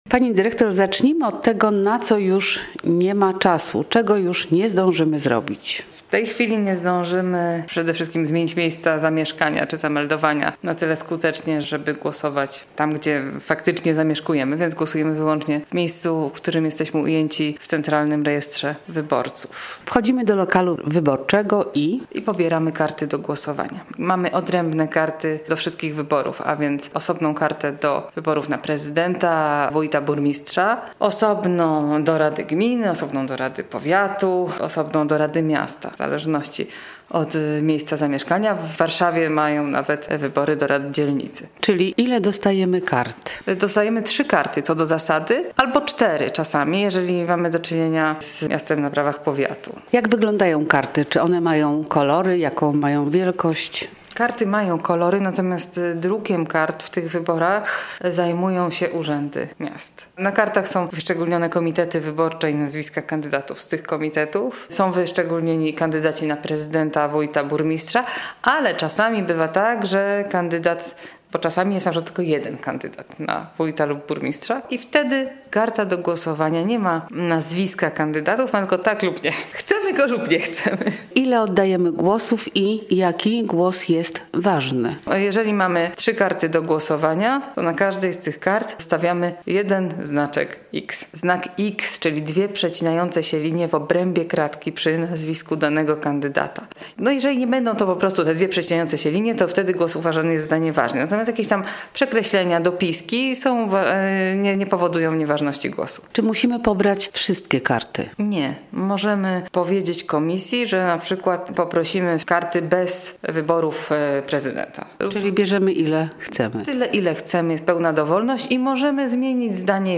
[ROZMOWA] - Radio Łódź